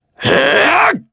Ezekiel-Vox_Casting3_jp_b.wav